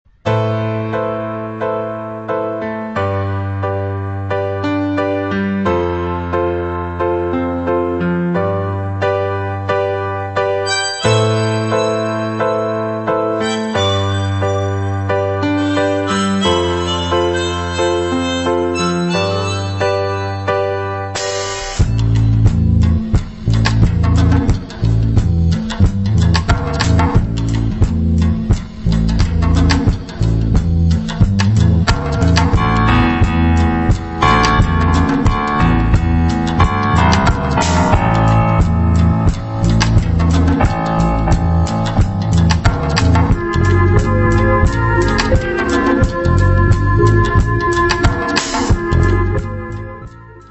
The place still has a knack for unusual atmospheres.